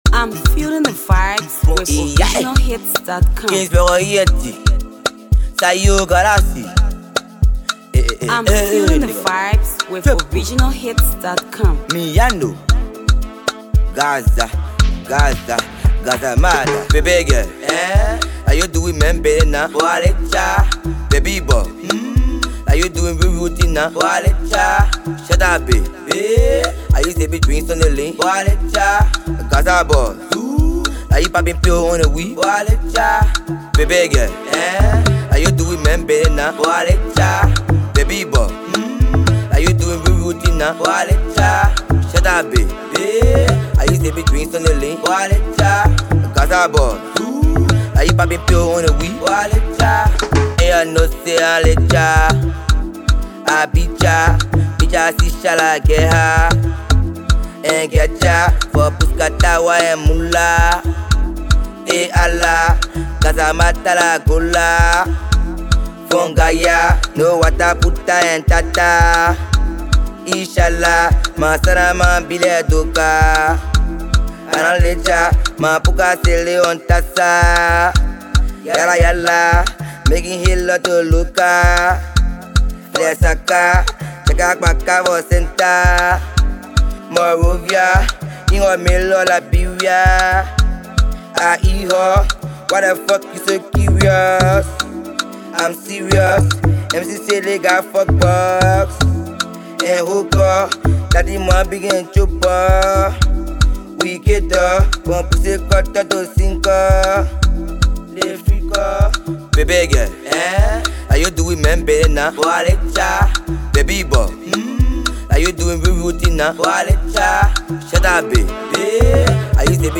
sensational rapper
latest studio effort